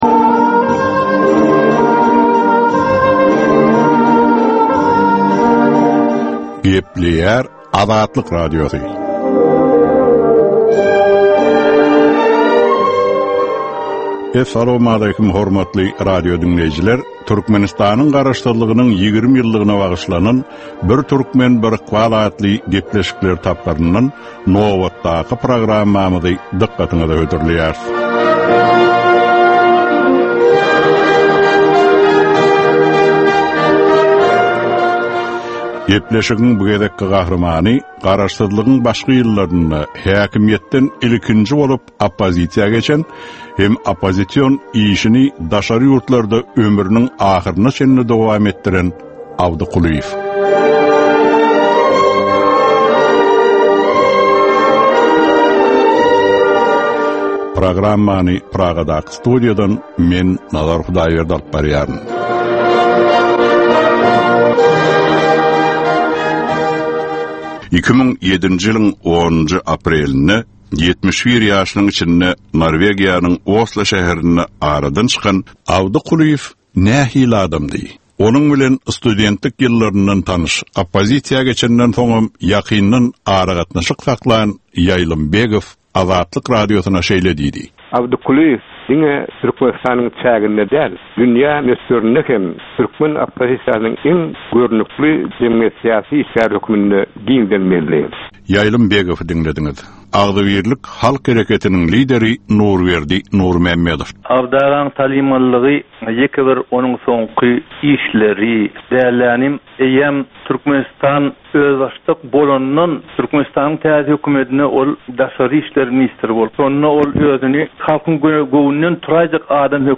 Türkmenistan we türkmen halky bilen ykbaly baglanyşykly görnükli şahsyýetleriň ömri we işleri barada ýörite gepleşik. Bu gepleşikde gürrüňi edilýän gahrymanyň ömri we işleri barada giňişleýin arhiw materiallary, dürli kärdäki adamlaryň, synçylaryň, bilermenleriň, žurnalistleriň we ýazyjy-sahyrlaryň pikirleri, ýatlamalary we maglumatlary berilýär.